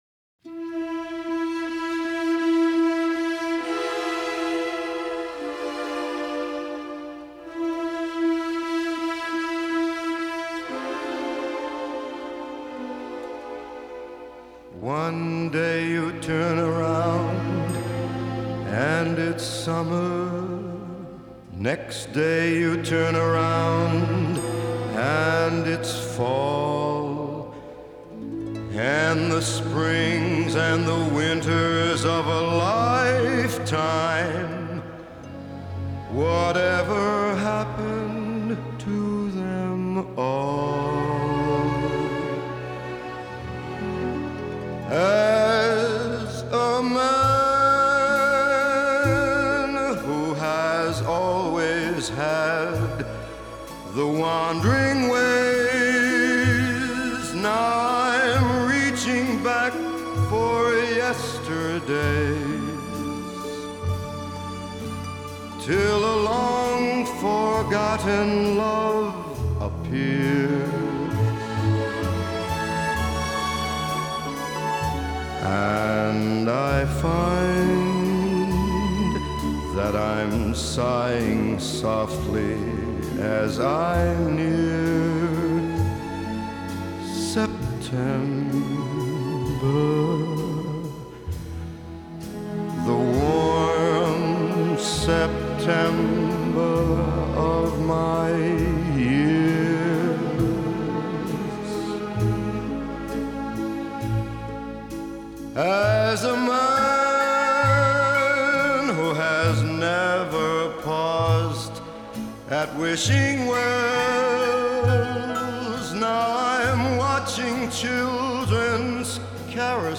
jazz
джаз